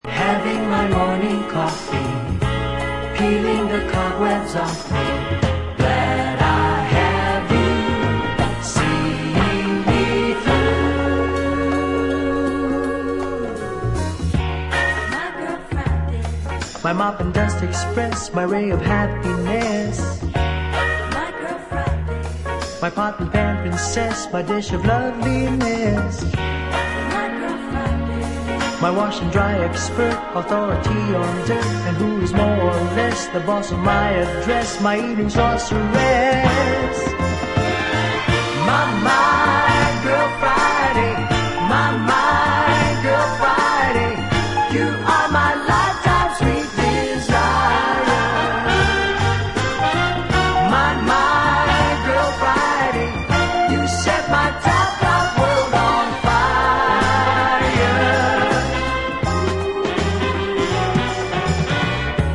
One for the proper soul heads that like to dig really deep.